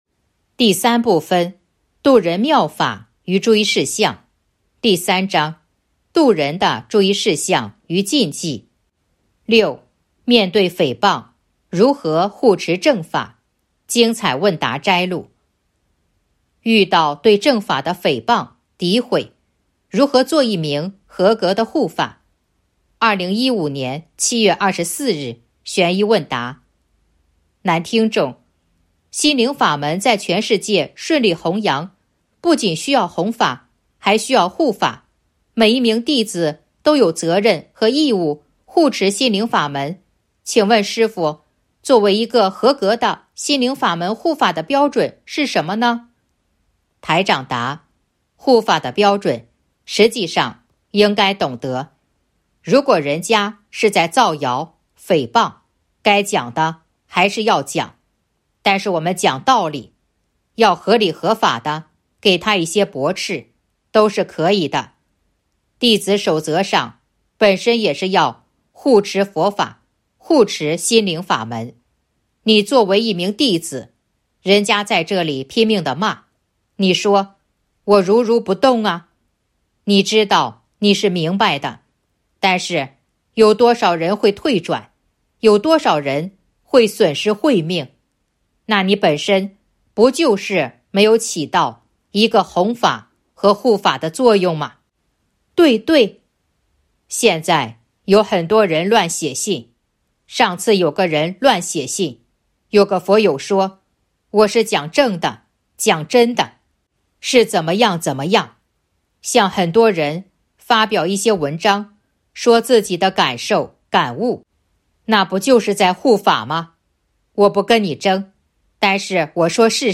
060.精彩问答摘录《弘法度人手册》【有声书】 - 弘法度人手册 百花齐放